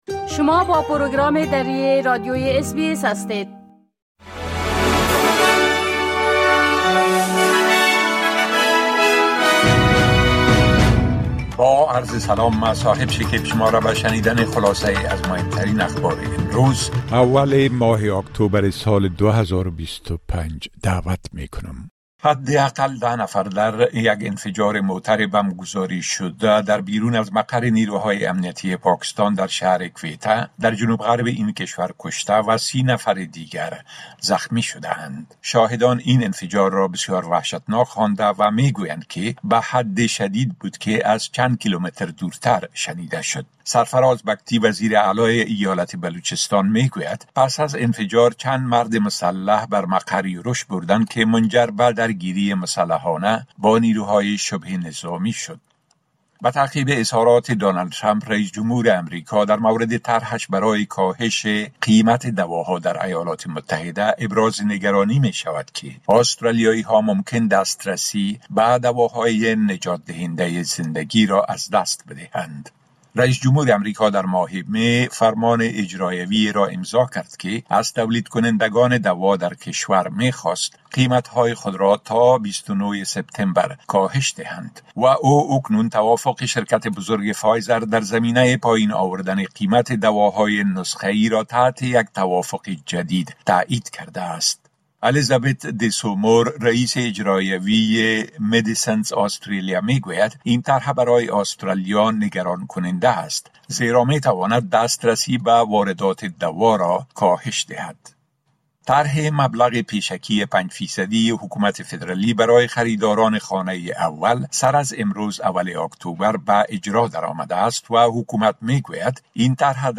خلاصه مهمترين خبرهای روز از بخش درى راديوى اس‌بى‌اس